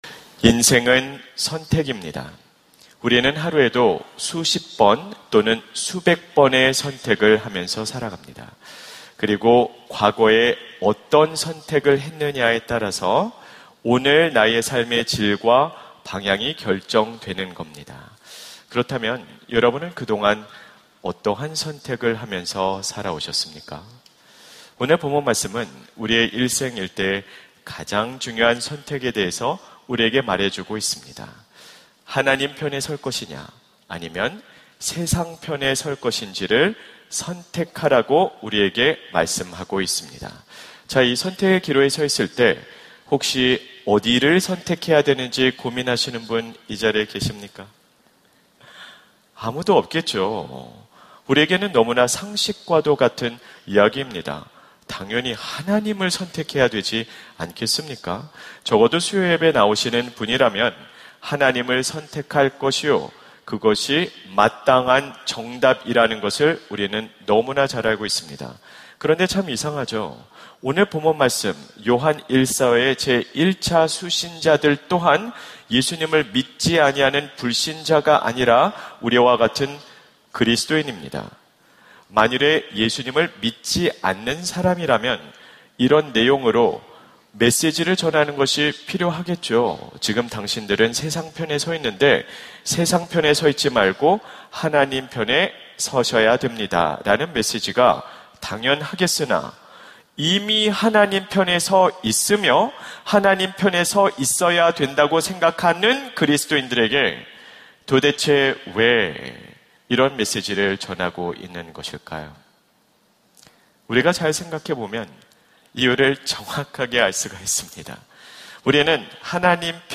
설교 : 수요향수예배 하나님의 숨결이 스며드는 일상 - 선택 : 확인해보셨나요? 설교본문 : 요한1서 2:15-17